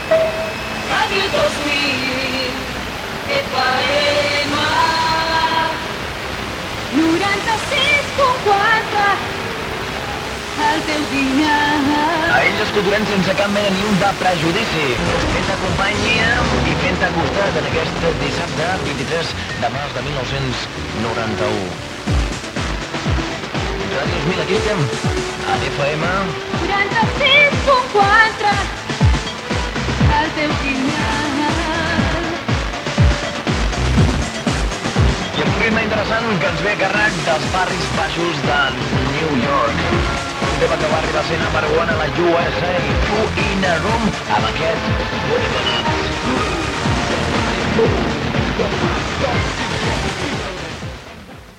Indicatiu i tema musical
Qualitat de l'àudio deficient.